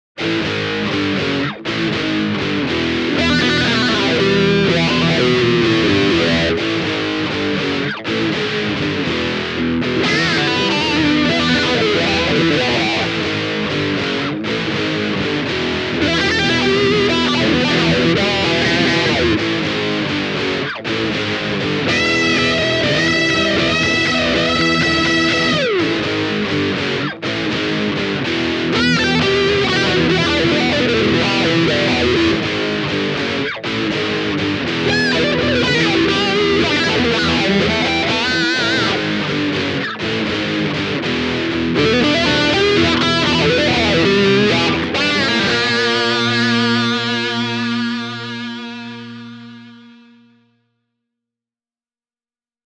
EMG’s 57/66-set comprises a pair of alnico-loaded humbuckers, and promises to deliver an intriguing combination of vintage warmth with active punch and clarity.
There’s more than enough power and punch on tap, but you could never call these active humbuckers cold, clinical or sterile!